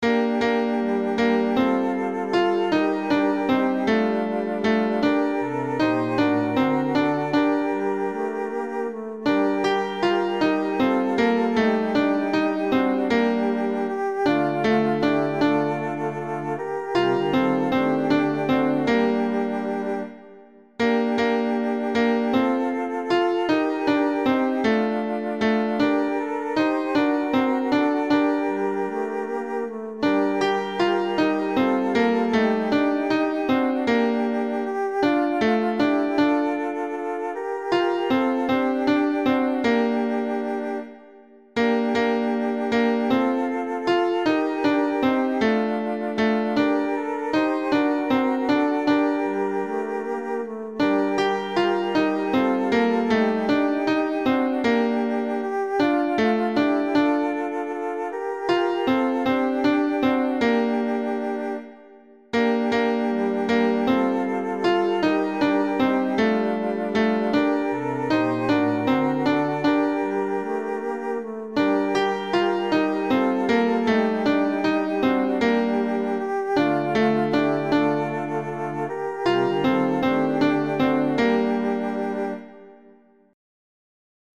tenor
A-Bethleem-Jesus-est-ne-tenor.mp3